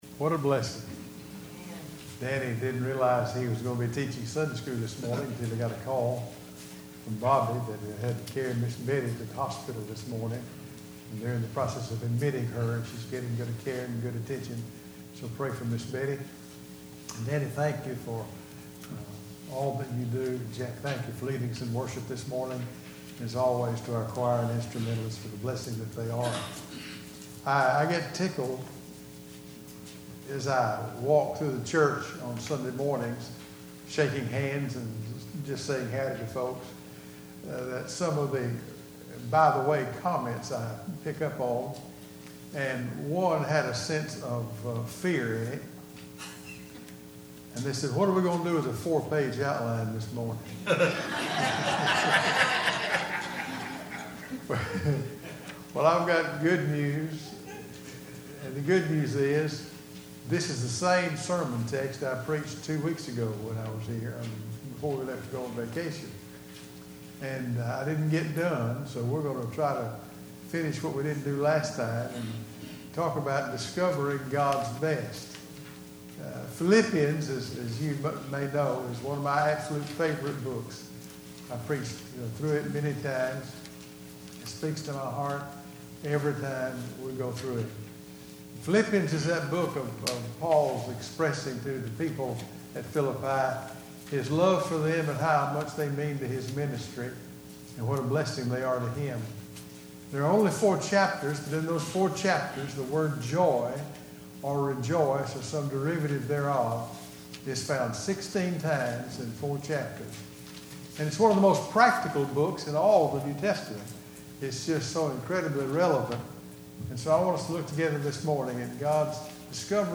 Sermons - Calvary Baptist Church